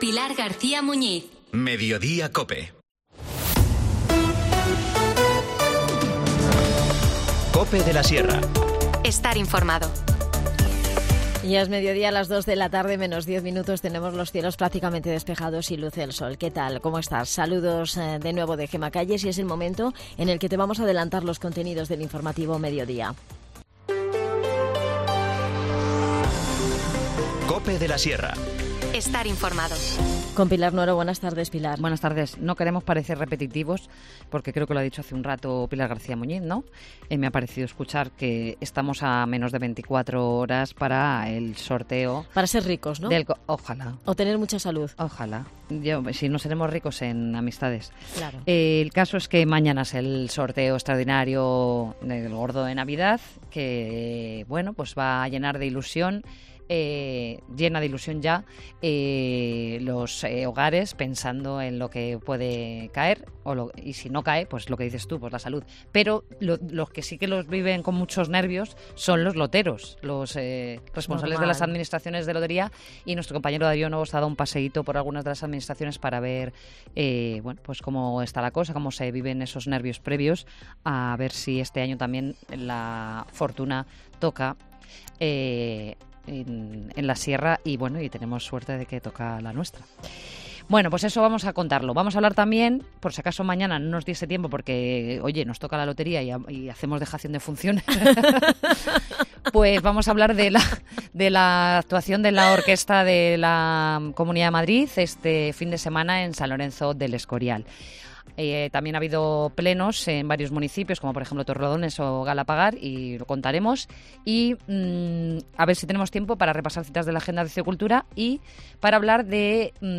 13:50 | Magazín| Mediodía COPE de la Sierra, 21 de diciembre de 2023